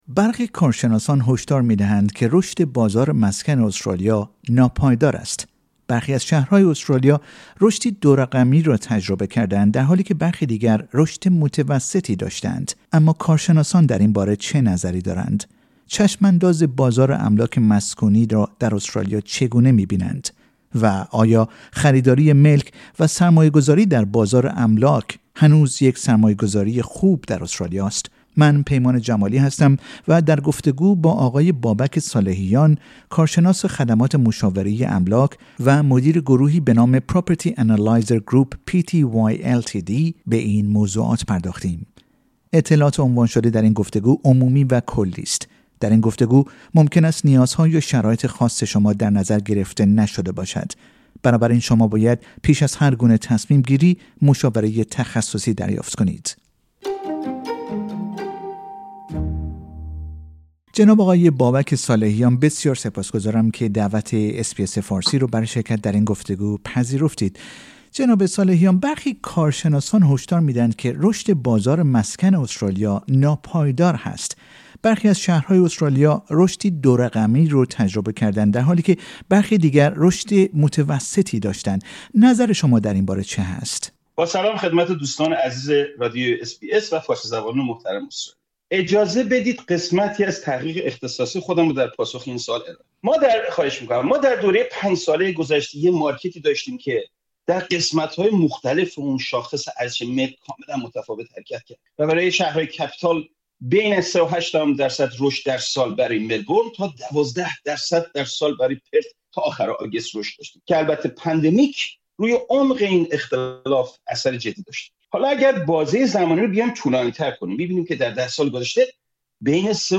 در این گفتگو ممکن است نیازها یا شرایط خاص شما در نظر گرفته نشده باشد.